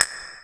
JJPercussion (43).wav